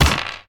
snd_diceroll.ogg